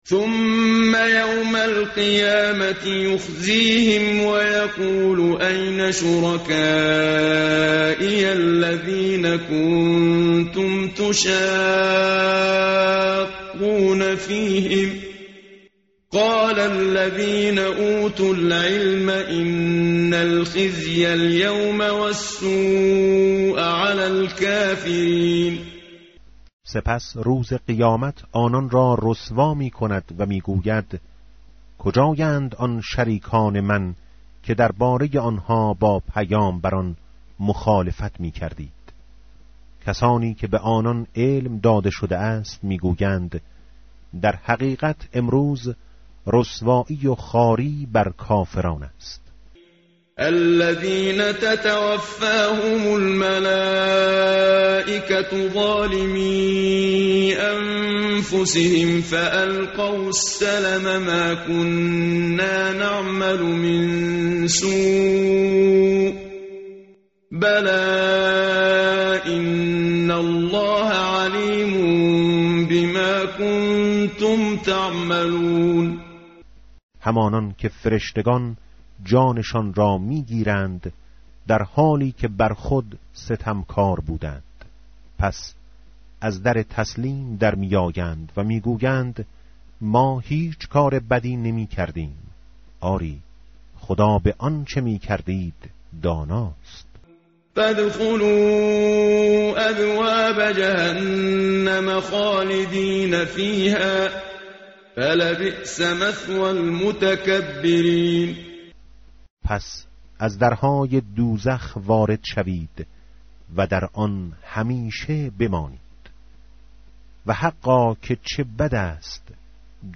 tartil_menshavi va tarjome_Page_270.mp3